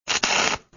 weld1.wav